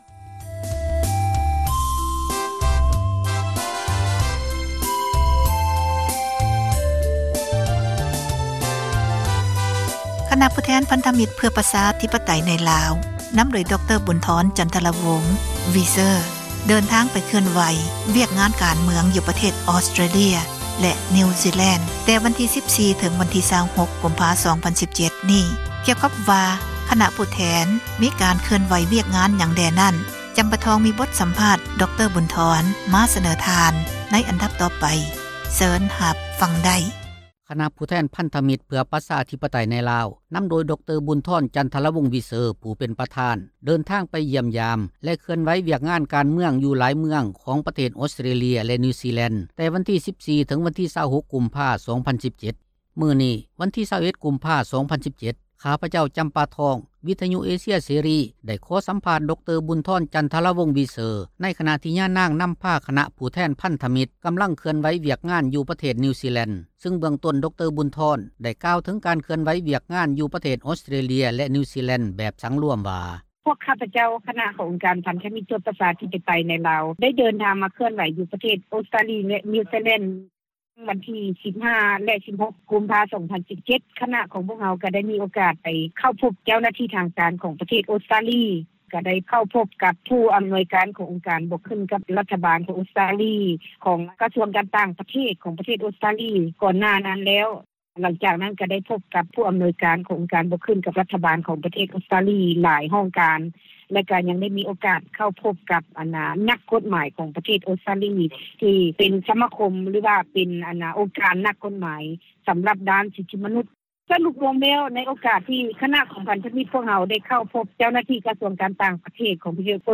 ສໍາພາດປະທານພັນທະມິດ